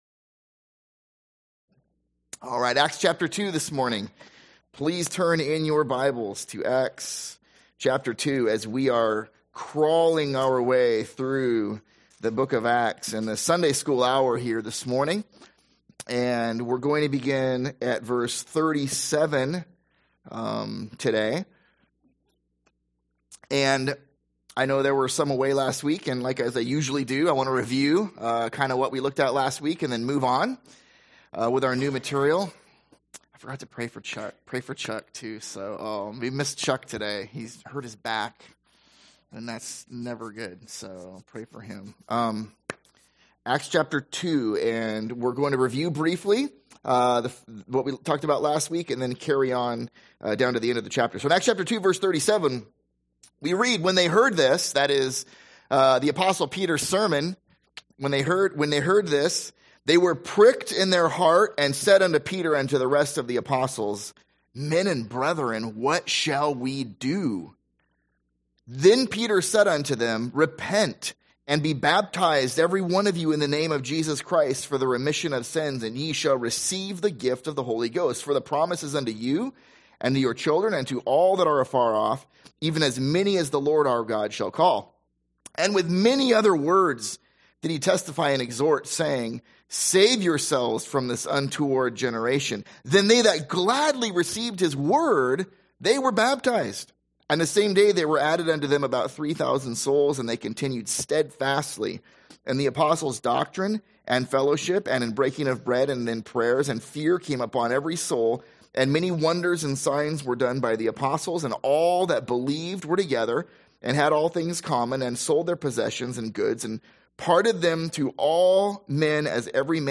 / A Sunday School series on the book of Acts